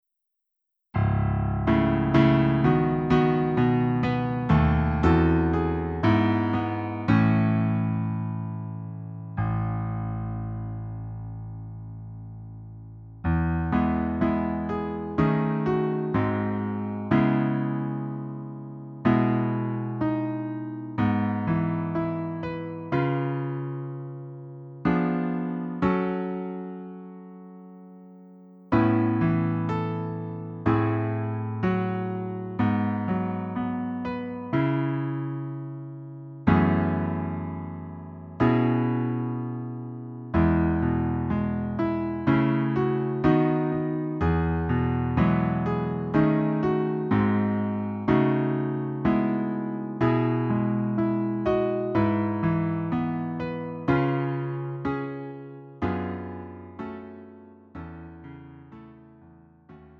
음정 원키 4:11
장르 구분 Lite MR